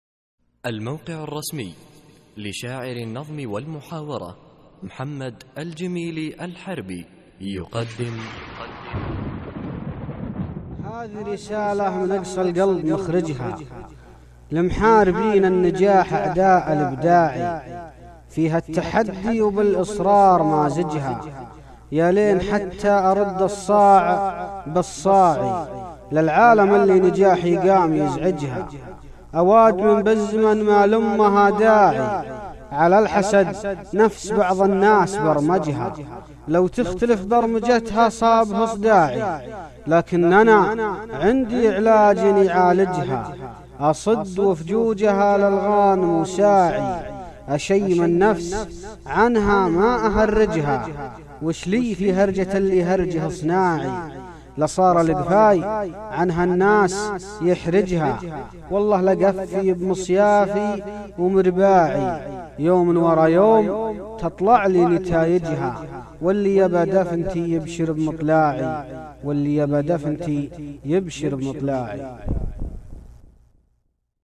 القصـائــد الصوتية
اسم القصيدة : أعداء الإبداع ~ إلقاء